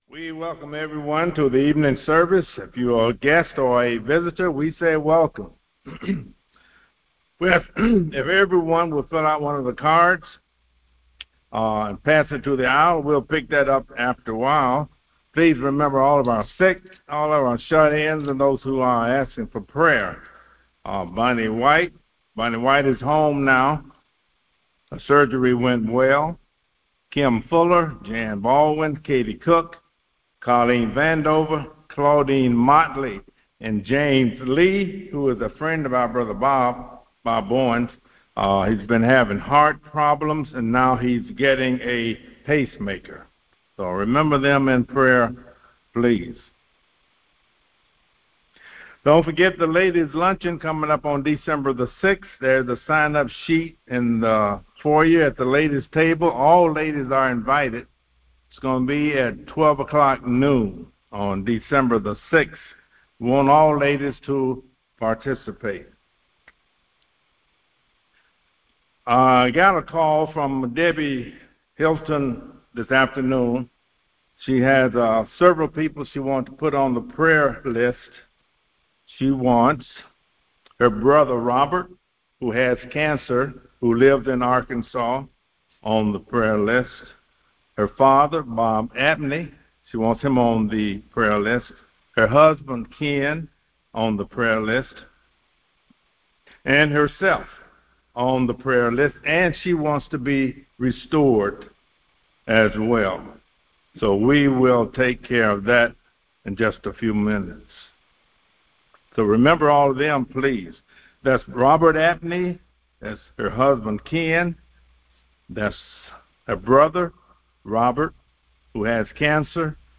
Sunday Evening Service 11.9.25